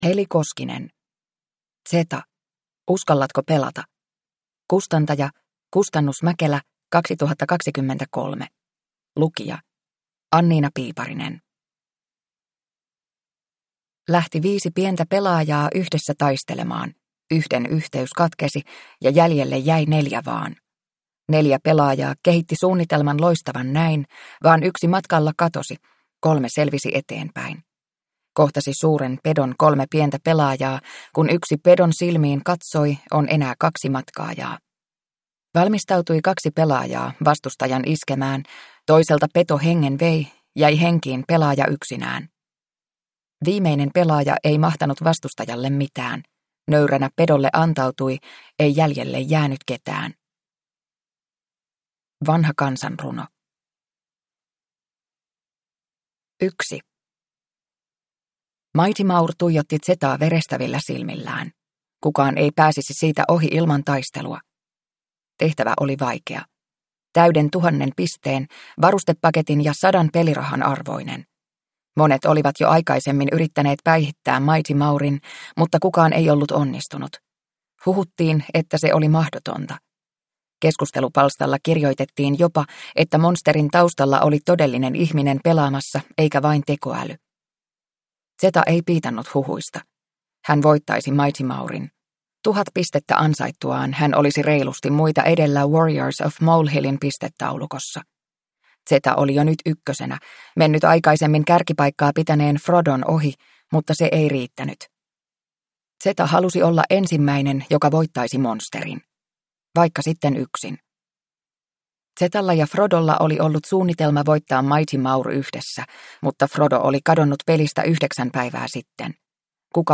Zeta: Uskallatko pelata? – Ljudbok